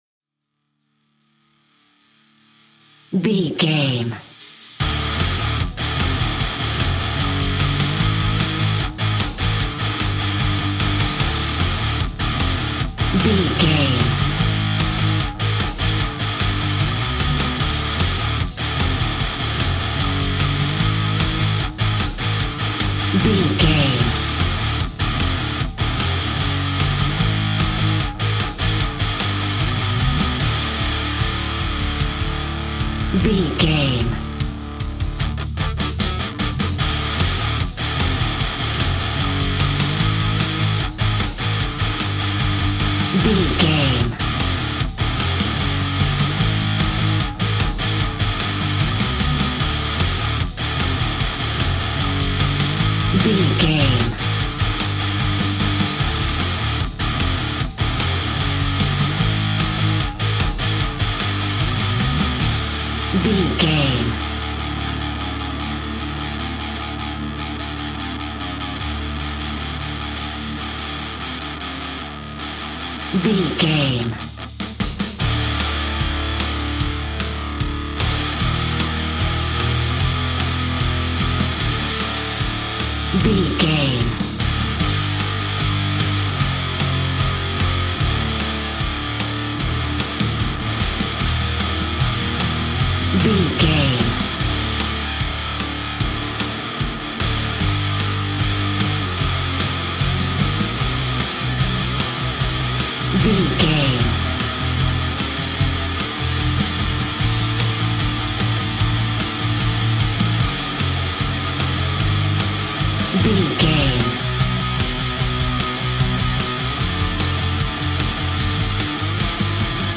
Epic / Action
Fast paced
Aeolian/Minor
hard rock
instrumentals
Rock Bass
heavy drums
distorted guitars
hammond organ